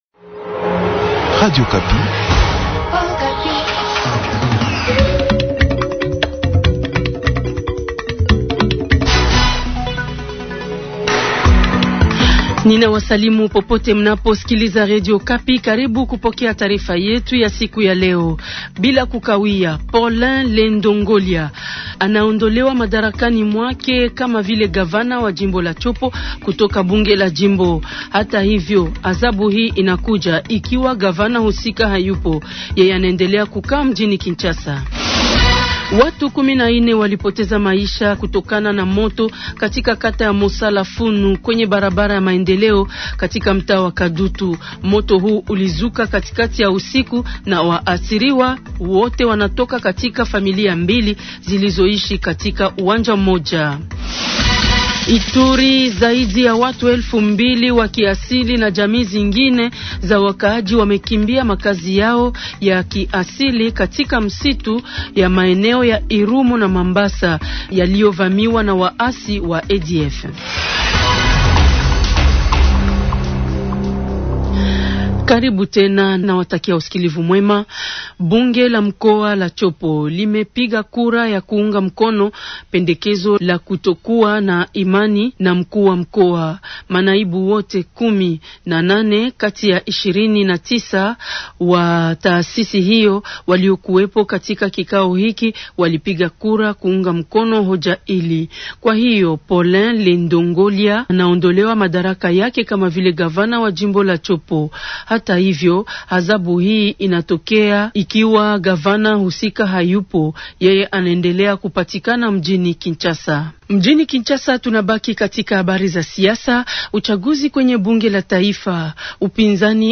Journal Swahili MATIN du mardi 28 octobre 2025.